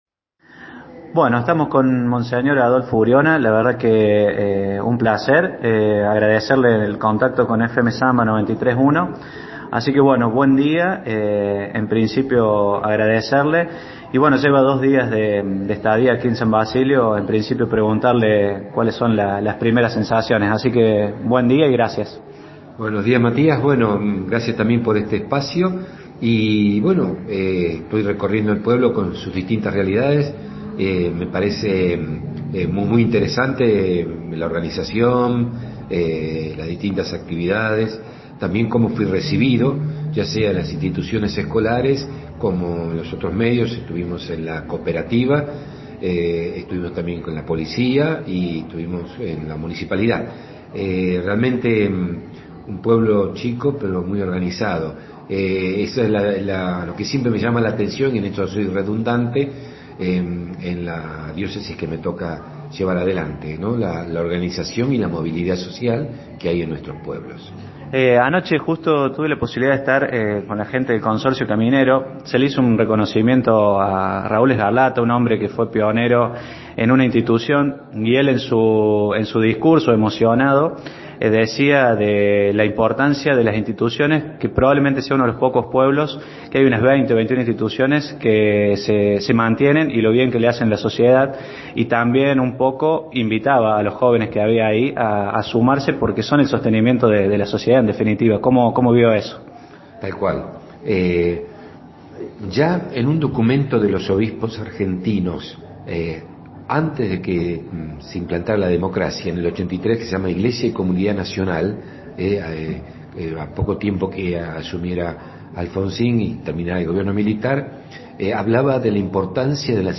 Compartimos el audio con la nota a Adolfo Uriona: